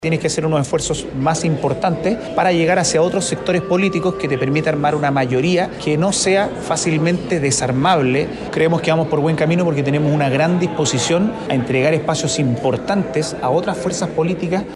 Benjamín Moreno, diputado republicano encargado de las negociaciones, dice que van por ‘buen camino’ porque tienen una ‘gran disposición’ a entregar espacios.